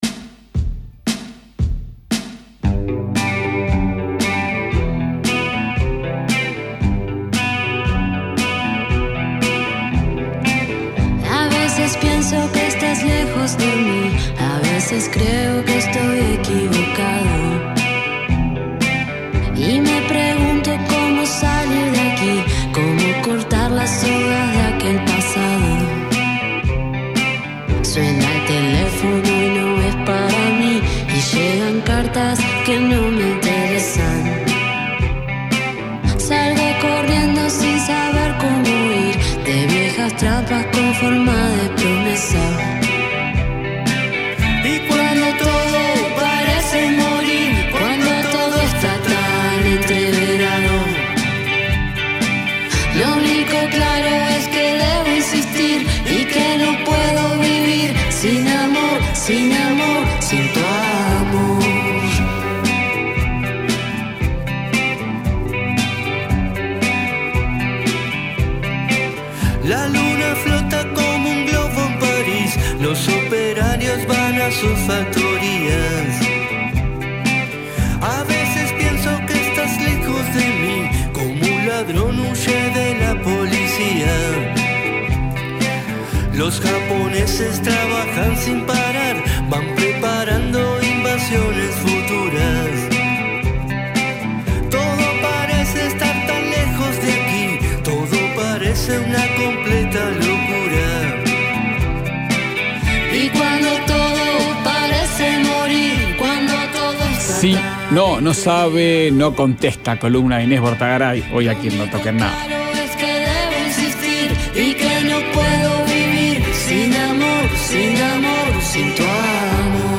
Tríada de encuesta, “yes persons”, sí que niegan y no que son no. Inés Bortagaray leyó a Carson McCullers e Idea Vilariño y habló de la obra de Yoko Ono que conquistó a John Lennon.